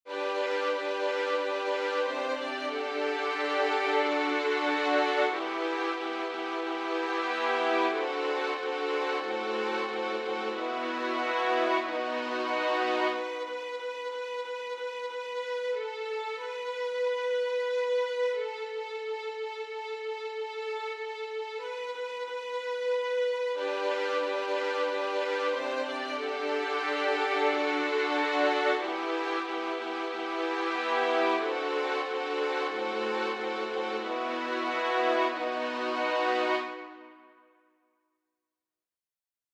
Chants d’Acclamations.